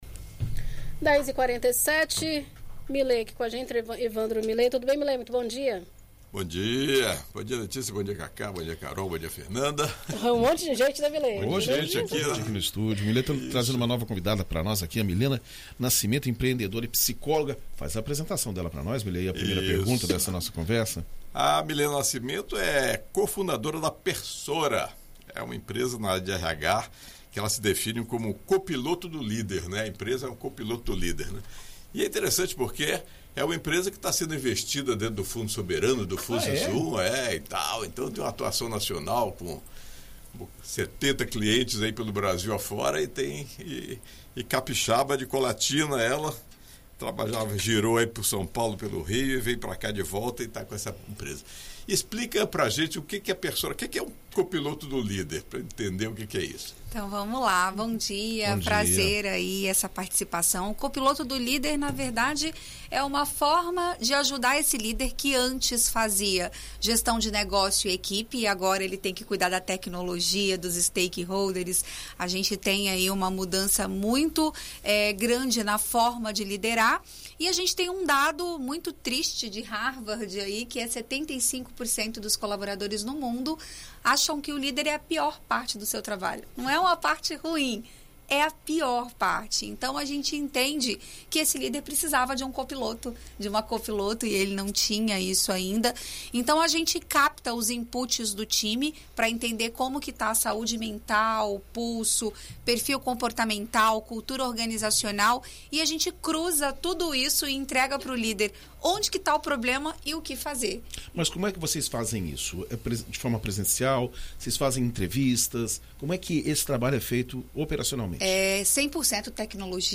recebe no estúdio